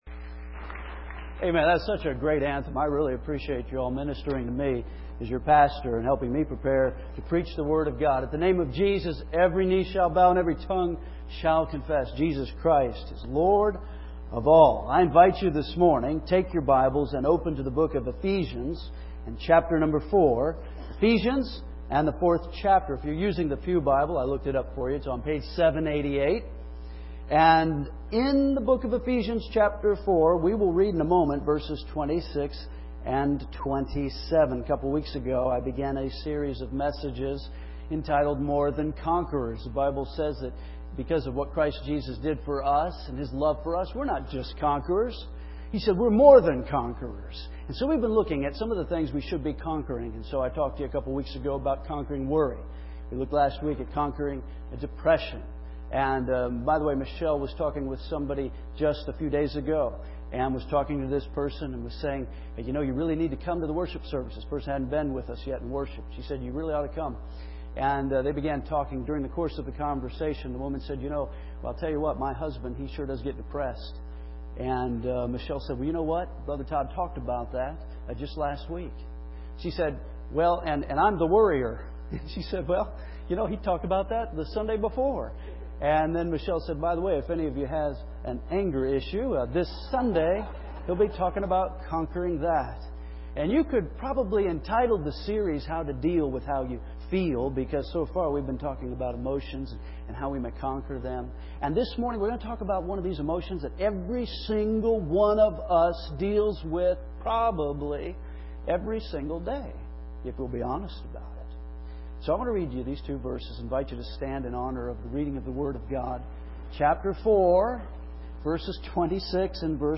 First Baptist Church, Henderson, KY